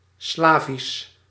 Ääntäminen
Synonyymit langue slave slavique esclavon Ääntäminen France: IPA: /slav/ Haettu sana löytyi näillä lähdekielillä: ranska Käännös Konteksti Ääninäyte Substantiivit 1.